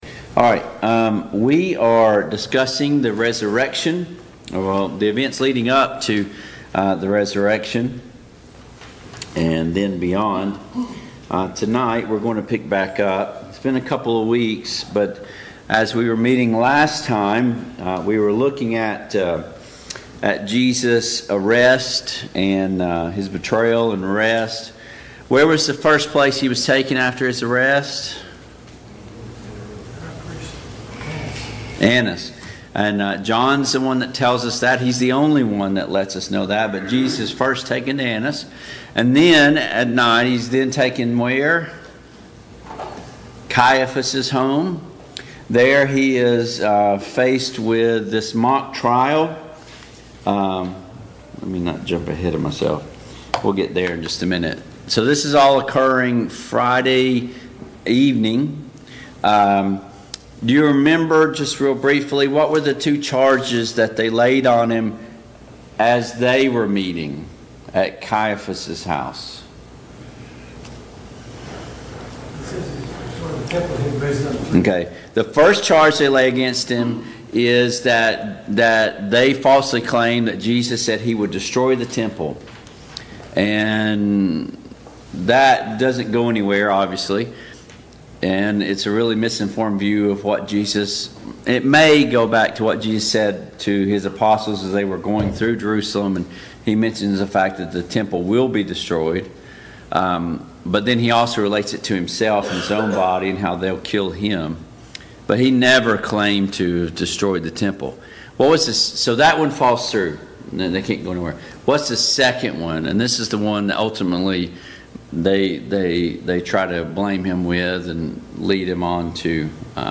Biblical Timeline of the Jesus’ Resurrection Service Type: Mid-Week Bible Study Download Files Notes Topics: Jesus goes before Pilate , Judas kills himself « 2.